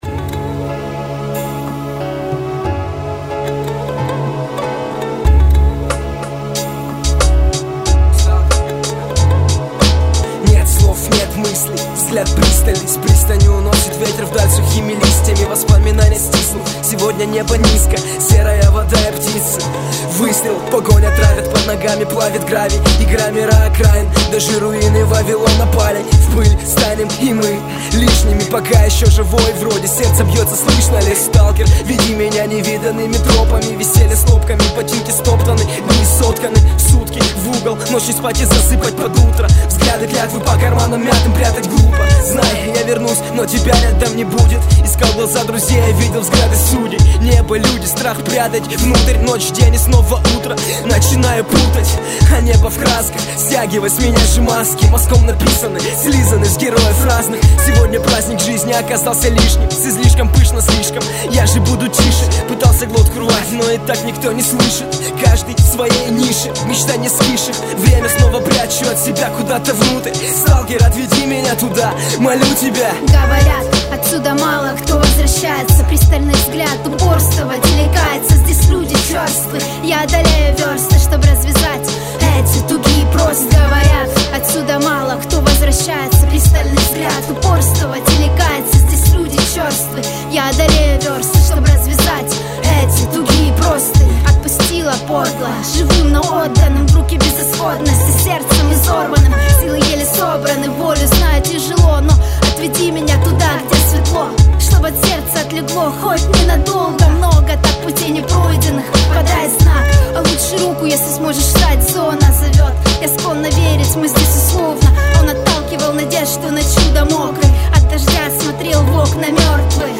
Реп сталкер [9]